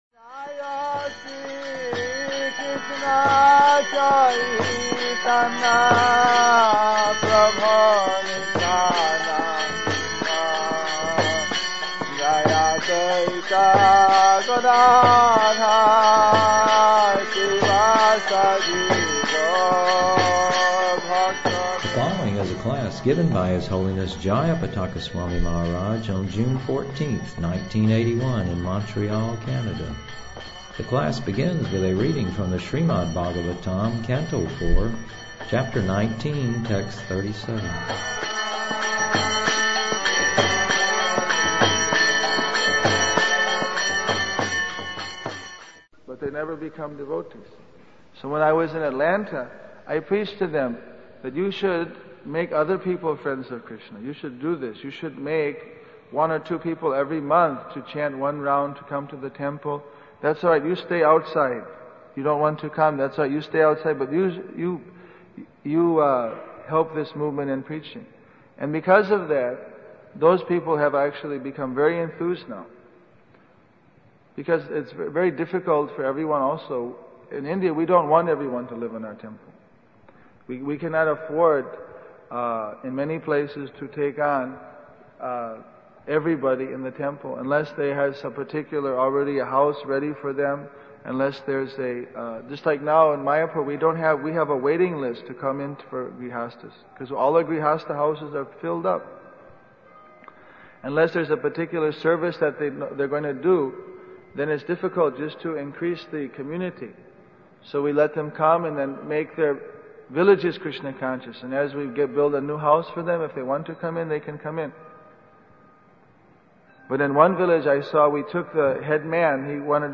The class begins with a reading from the Śrīmad-Bhāgavatam, Canto 4, Chapter 19, Text 37.